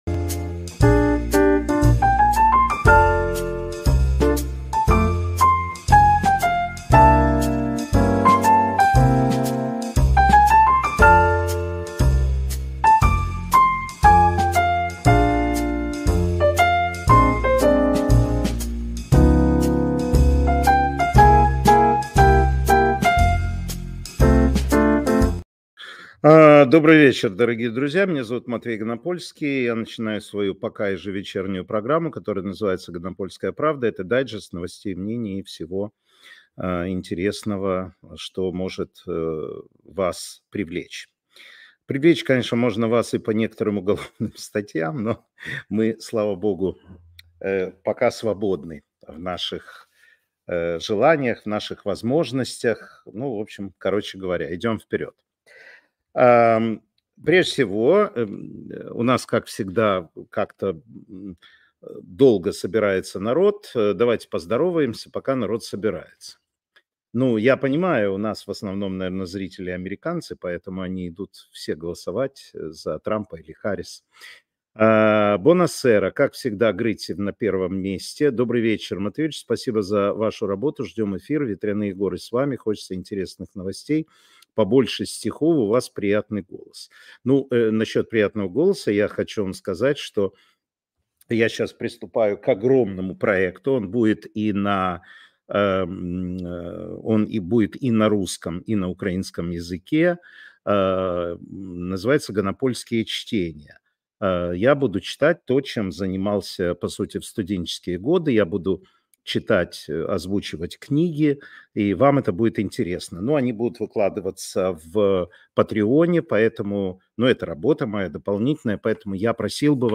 Эфир Матвея Ганапольского